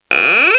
00020_Sound_squeek3
1 channel